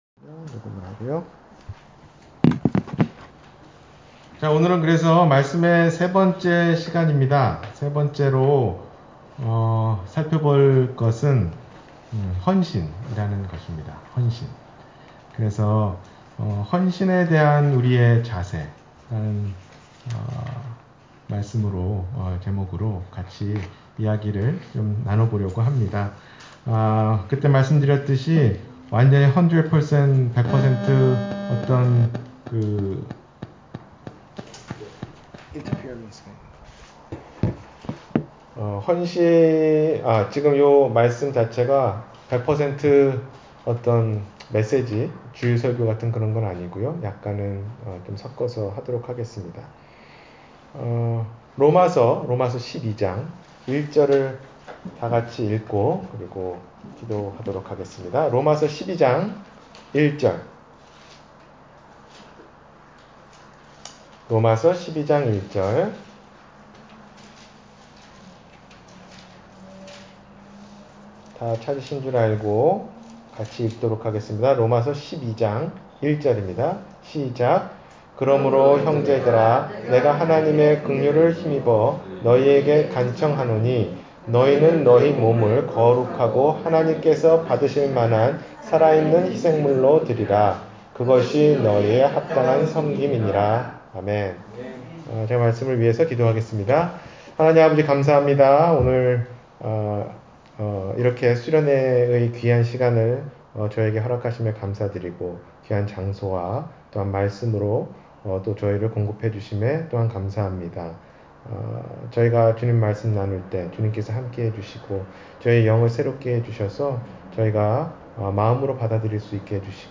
Service Type: 2019 수련회